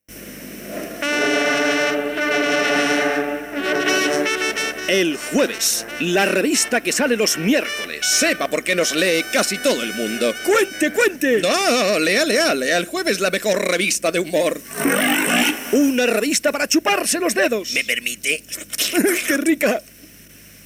Anunci de la revista "El Jueves".
FM